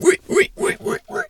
pig_2_hog_seq_08.wav